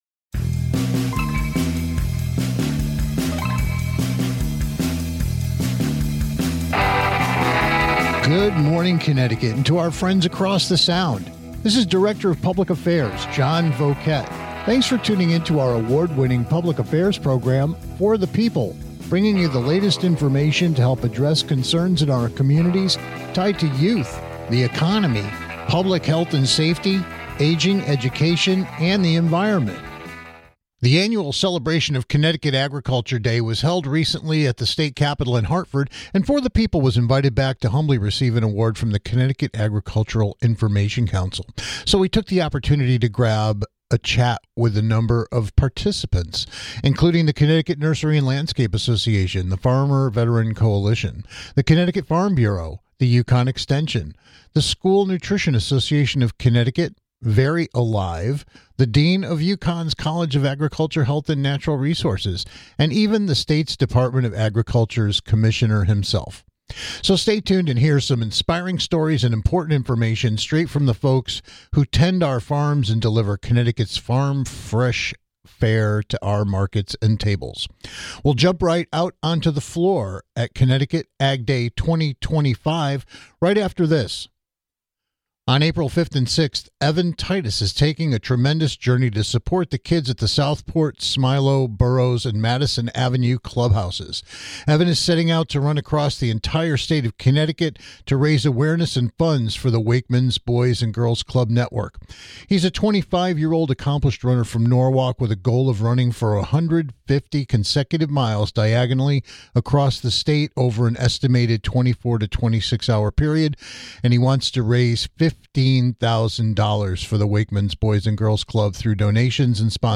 Special Report: CT Ag Day 2025 LIVE from the State Capitol